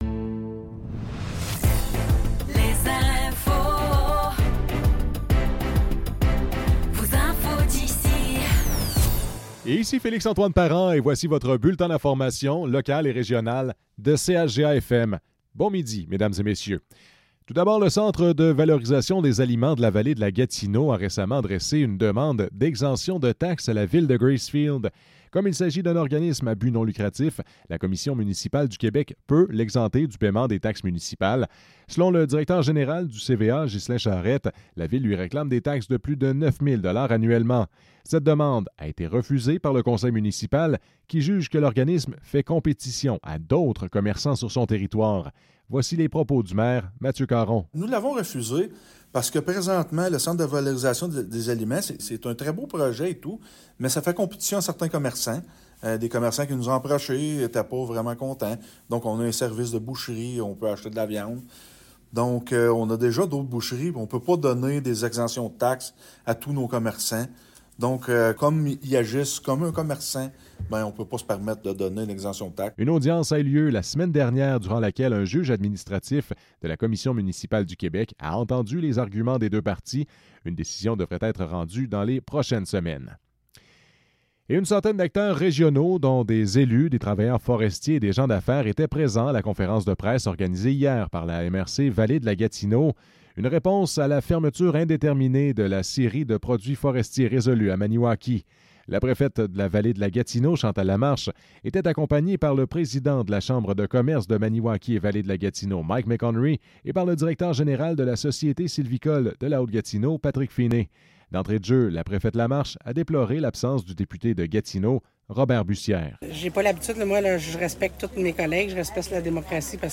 Nouvelles locales - 10 octobre 2024 - 12 h